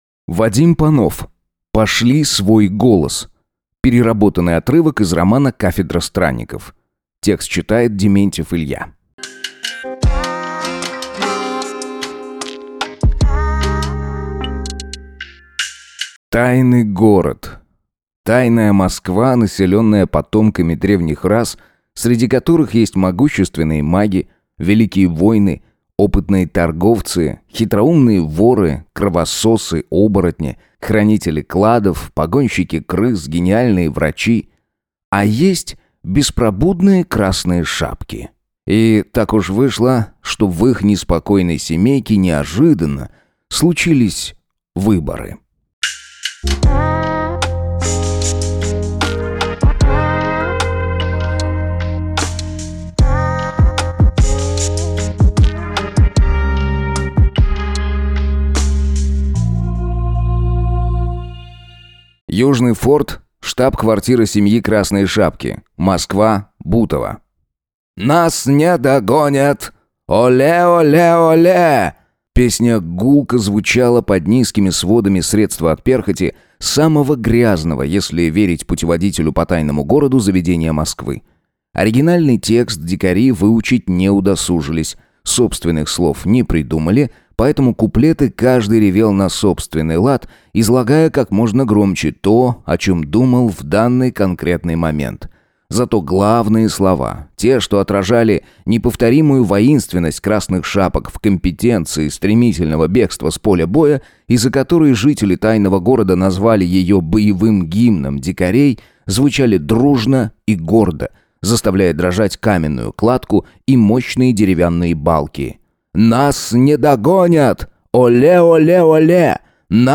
Аудиокнига Пошли свой голос | Библиотека аудиокниг